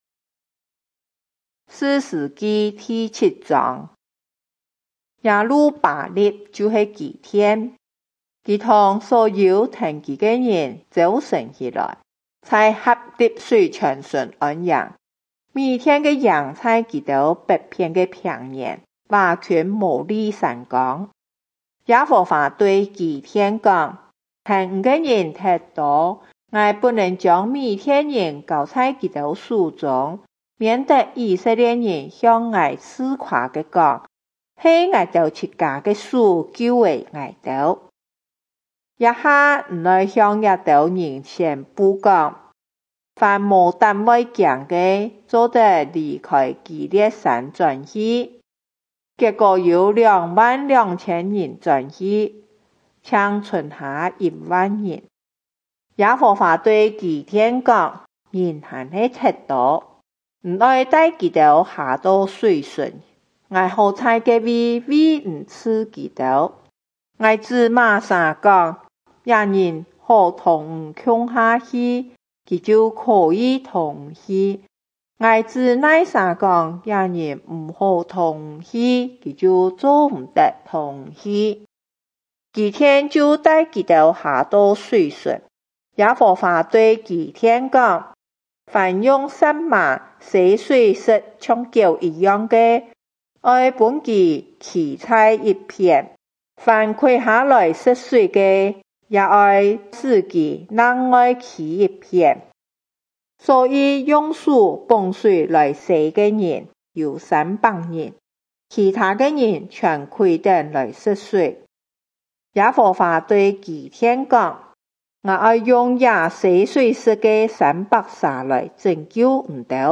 客家話有聲聖經 士師記 7章